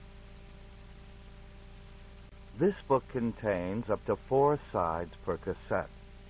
noisyvoice.ogg